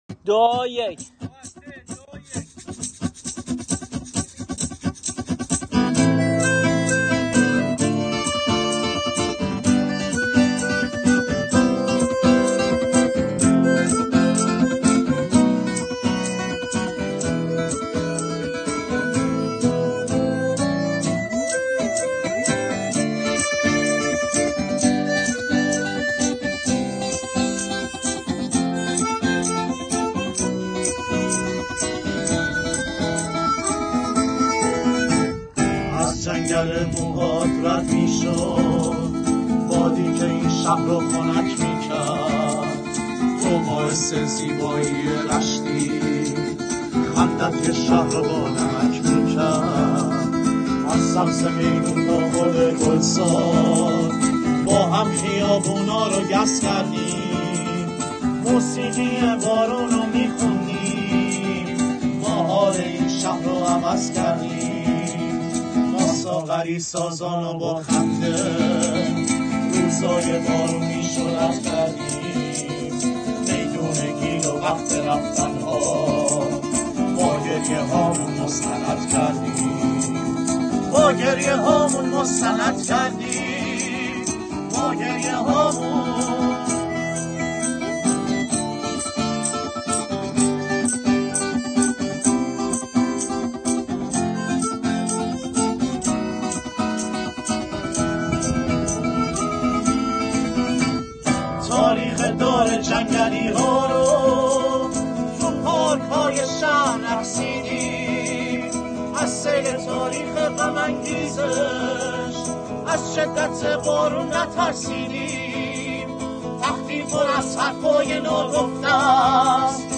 موسیقی خیابانی